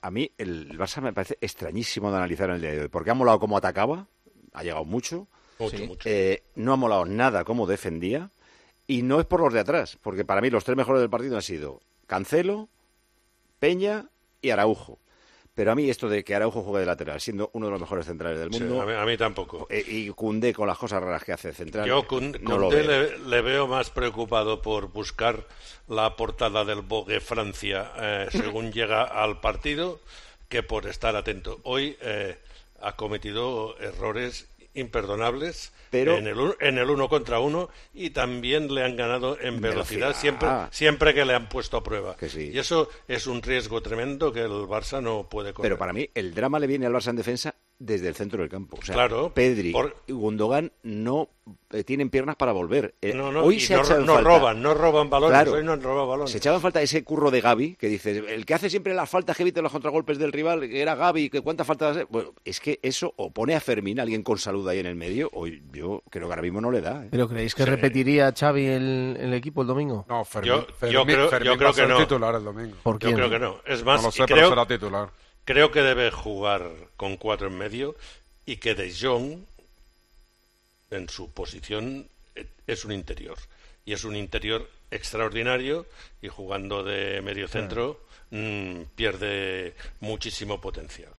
El equipo de Xavi Hernández selló su pase de ronda en la Champions League. Paco González aseguró en Tiempo de Juego que el el conjunto blaugrana era "extrañísimo de analizar".